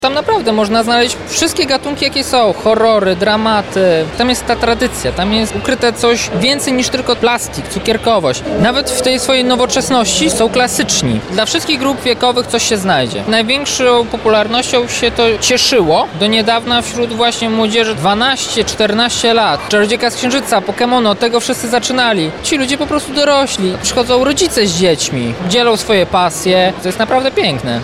przekonuje gość porannej audycji Radia Lublin